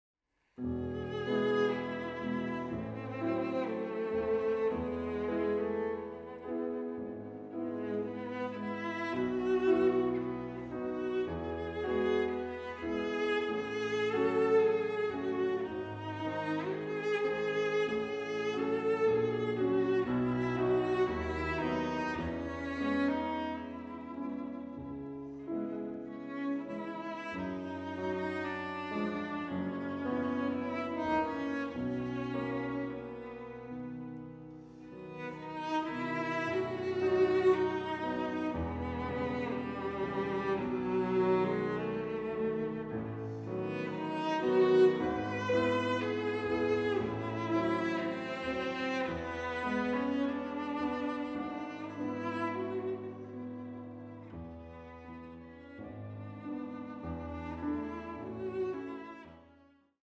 Kammermusik zwischen Romantik und Exil